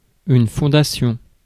Ääntäminen
France: IPA: [fɔ̃.da.sjɔ̃]